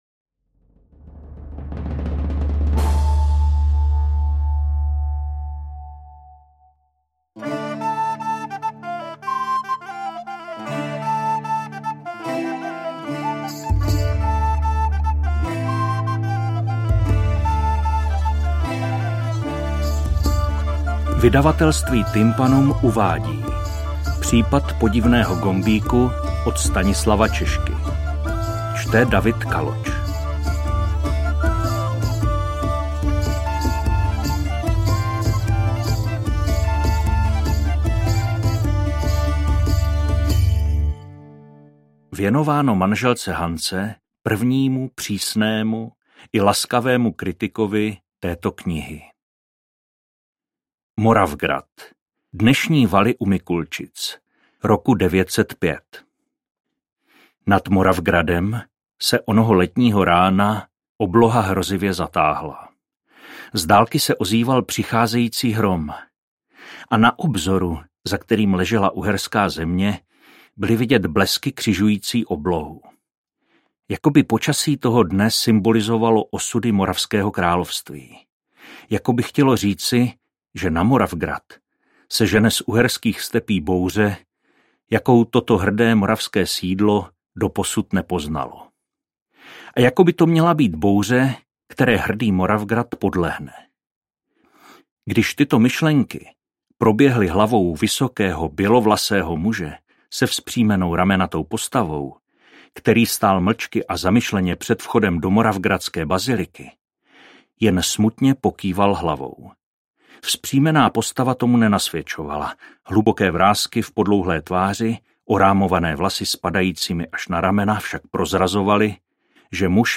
Interpret: